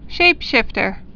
(shāpshĭftər)